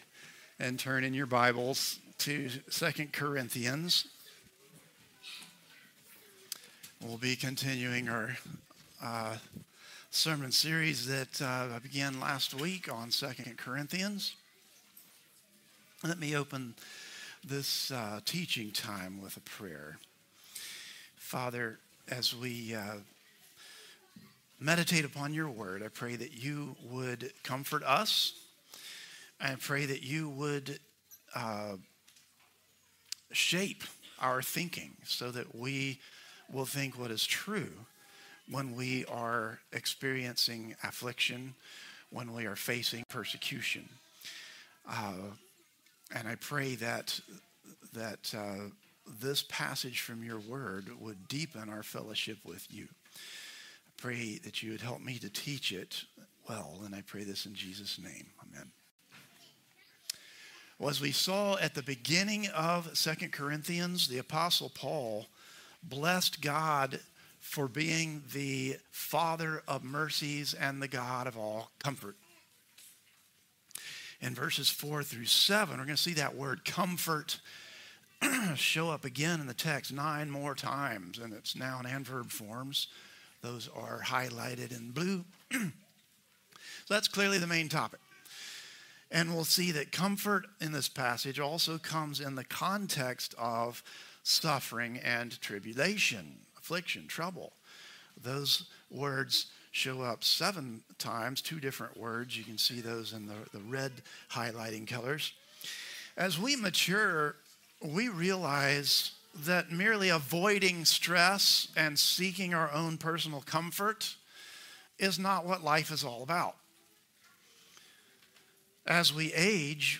Christ the Redeemer Church | Sermon Categories War with Sin